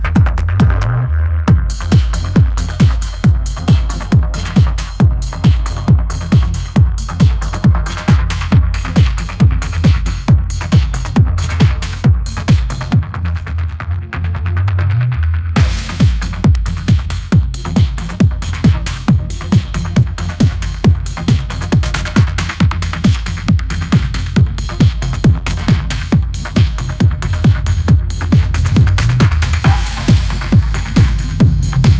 If someone wants to try generative AI music/looper maker I have a Colab that does that.